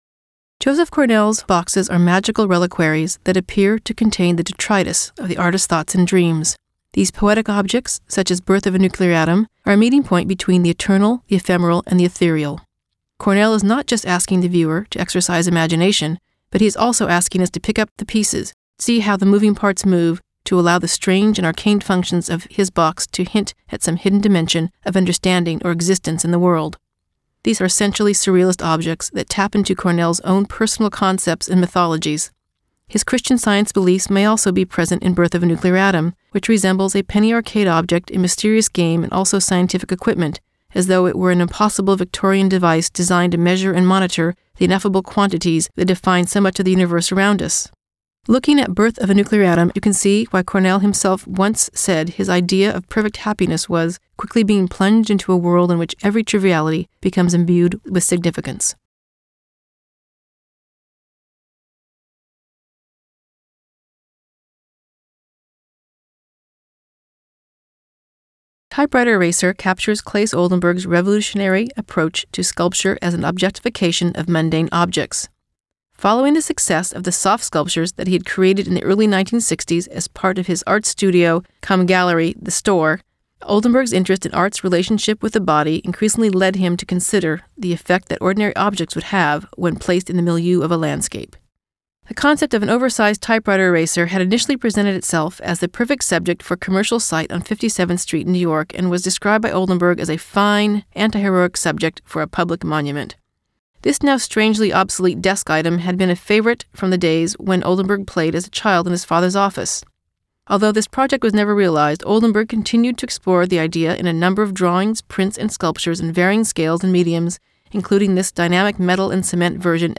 Short gallery talks by Christie's Specialists about fine art, antiques, wine, jewelry and watches.